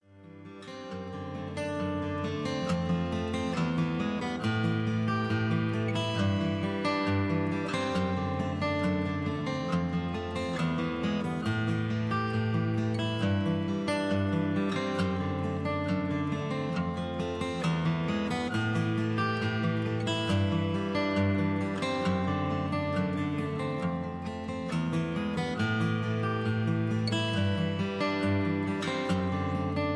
Tags: backingtracks , rock